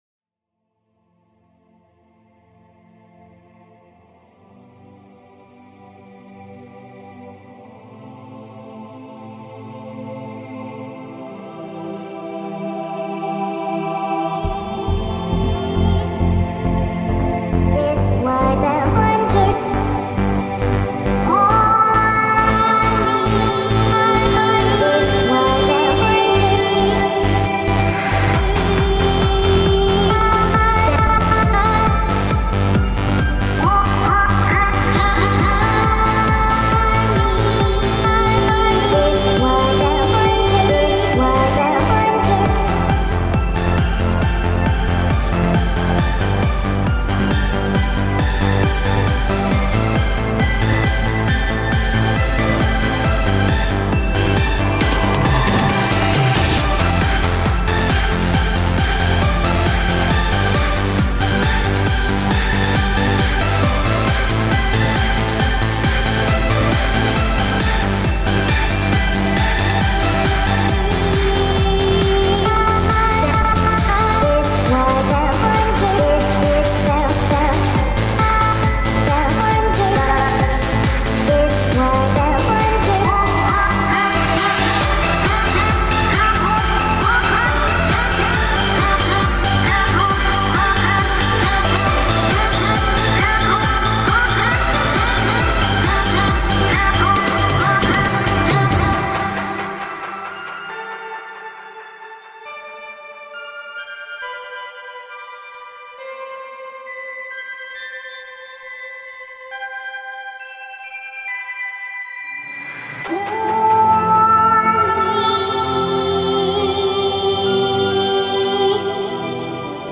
FL Studio 11 BPM: 136 Vocals: Accapella version